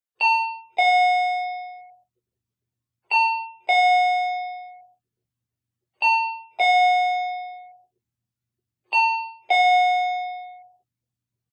Звуки дверного звонка
Электронный звук звонка